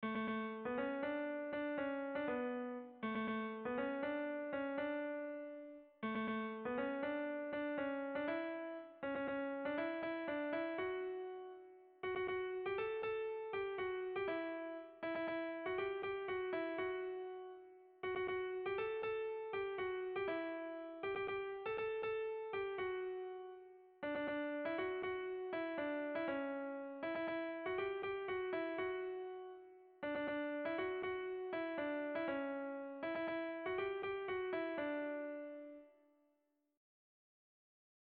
Tragikoa
Hamarreko handia (hg) / Bost puntuko handia (ip)